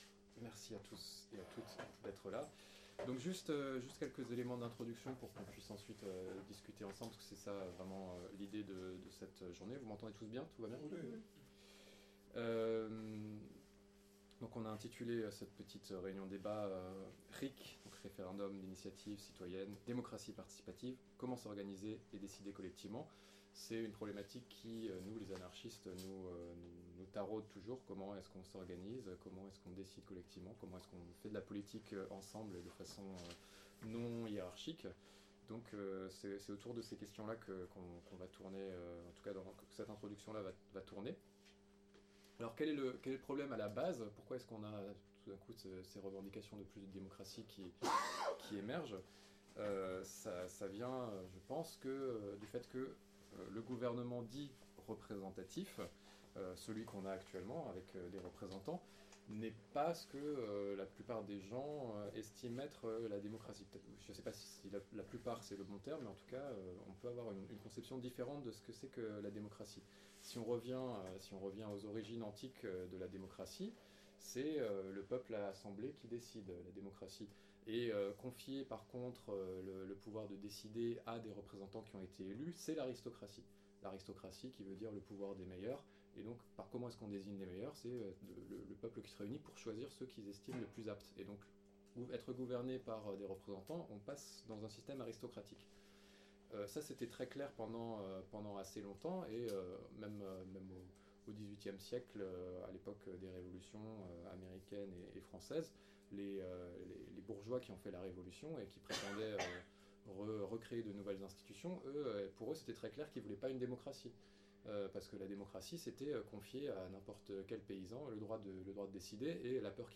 Accueil > Réunions publiques > RIC, démocratie participative : comment s’organiser et décider collectivement (...)